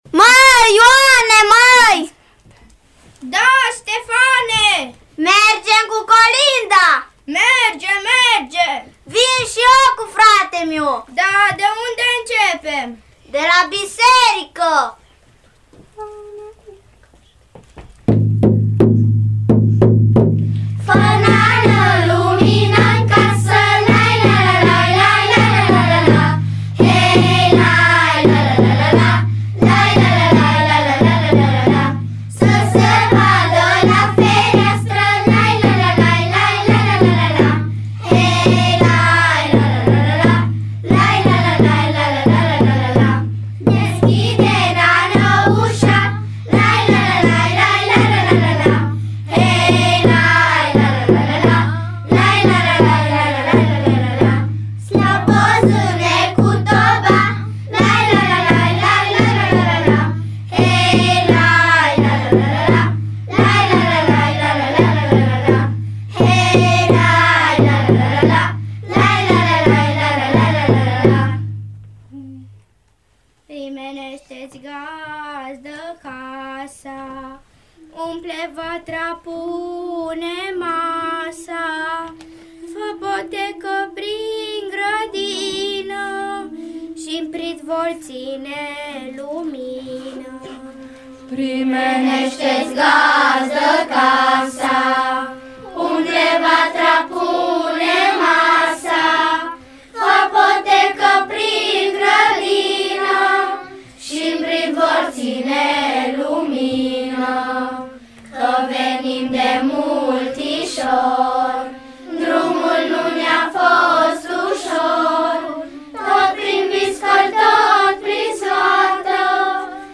Primii colindători la Radio Timișoara
Primii colindători au venit astăzi la Radio Timișoara.
micii colindători au demonstrat că au deprins arta colindatului, printr-un repertoriu interesant.